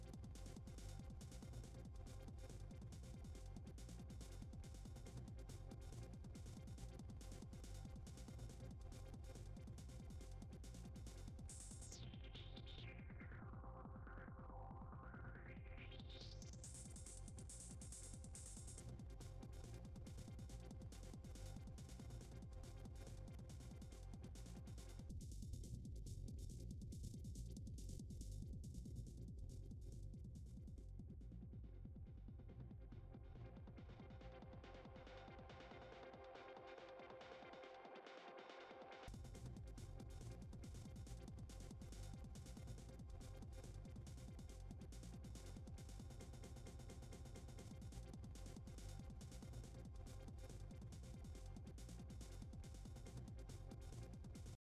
SP404 mk2 and the S-1